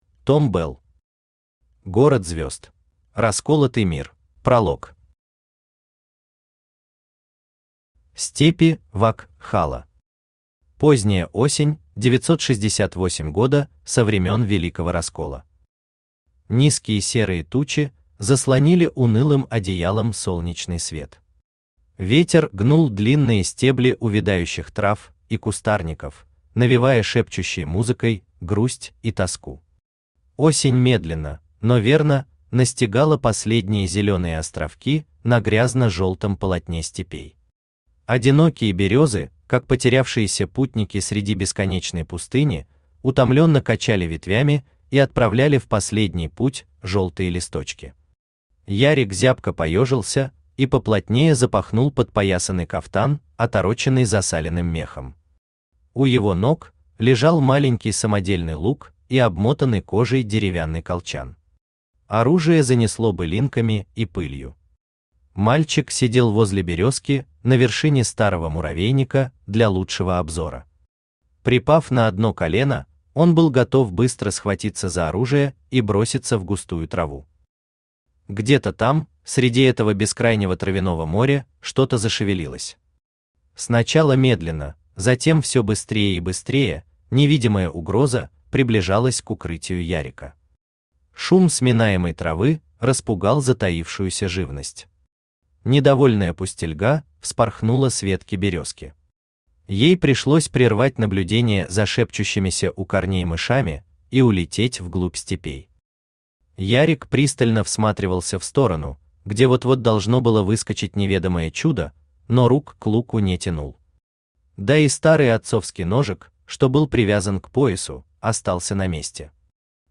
Аудиокнига Город Звёзд | Библиотека аудиокниг
Aудиокнига Город Звёзд Автор Том Белл Читает аудиокнигу Авточтец ЛитРес.